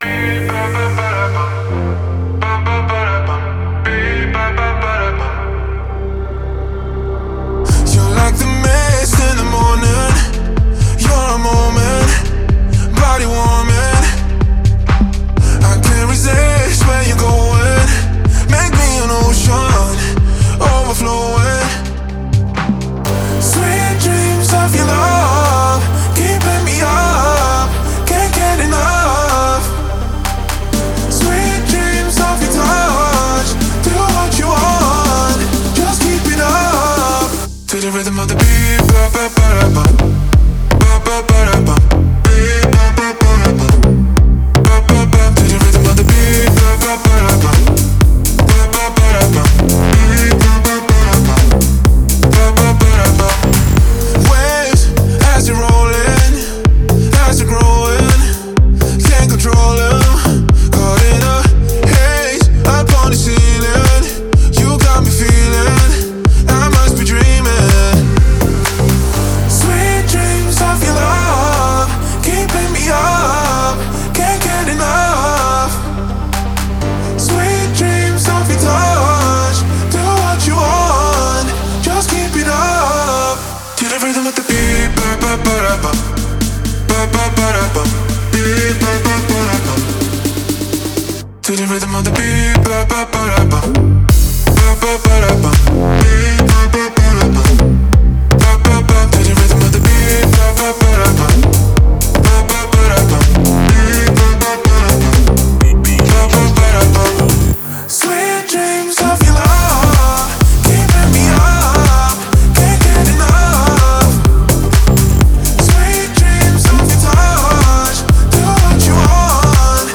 это энергичная EDM-композиция